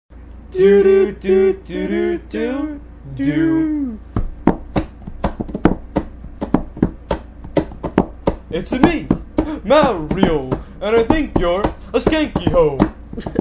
lead vocals
drums and harmonic vocals